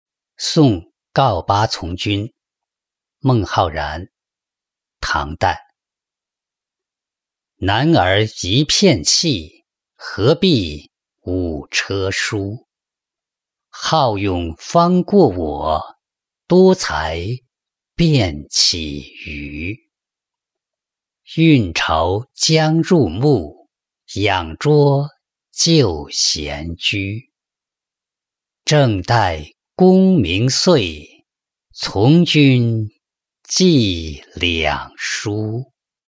送告八从军-音频朗读